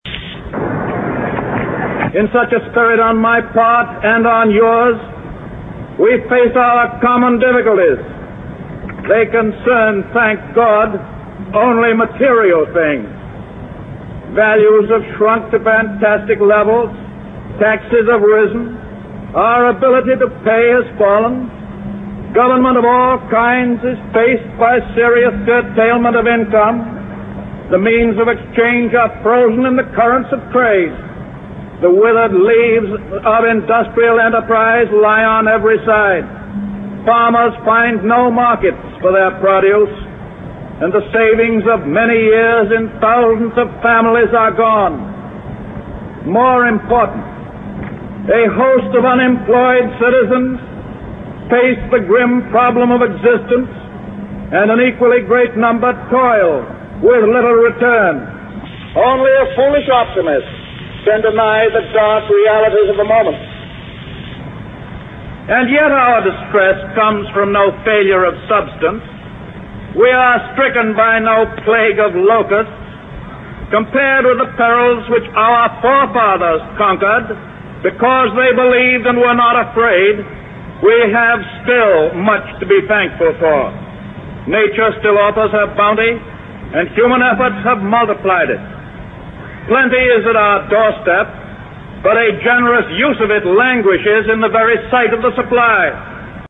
名人励志英语演讲 第17期:我们唯一害怕的是害怕本身(2) 听力文件下载—在线英语听力室